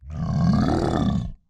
imp_drag.wav